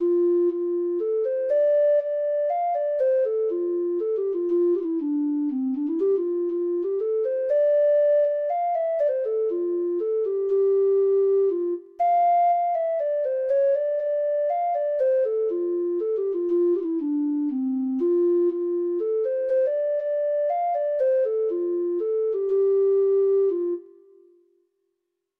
Traditional Music of unknown author.